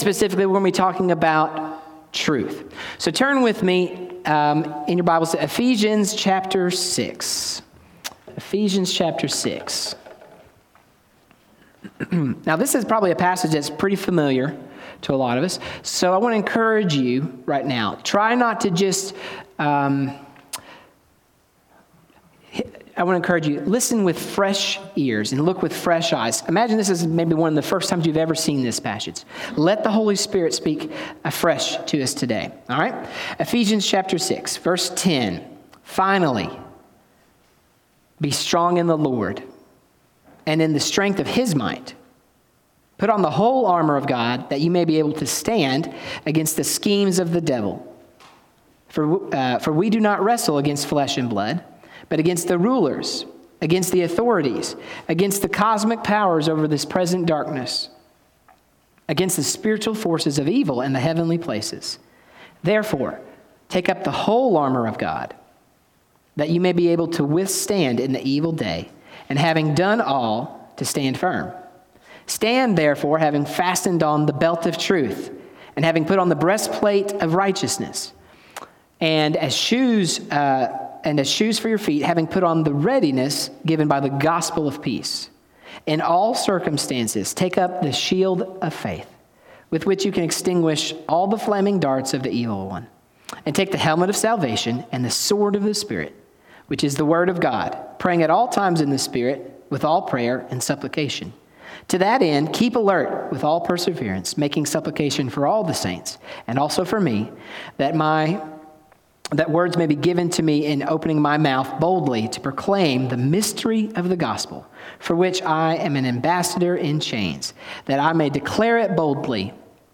Sermons | Robertsville Baptist Church